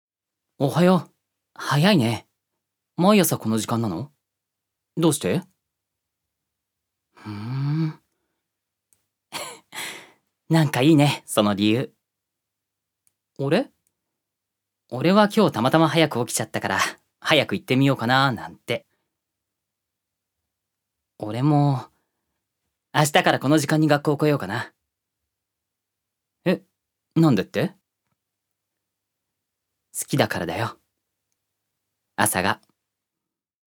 預かり：男性
セリフ２